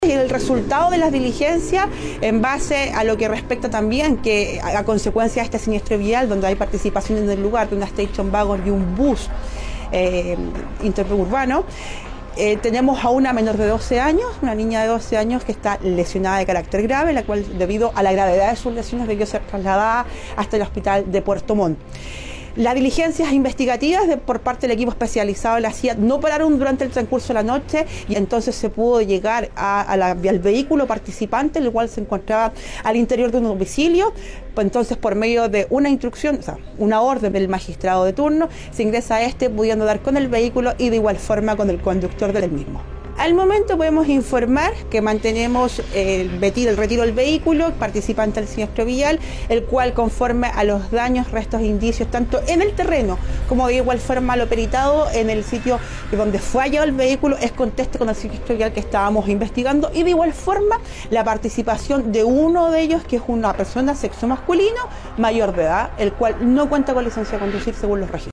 La oficial de Carabineros sostuvo que gracias a la indagatoria se pudo dar con el vehículo y con su conductor, verificando que era el móvil que había participado en el siniestro del viernes por la tarde y deteniendo a este hombre, quien no cuenta con licencia de conducir vigente.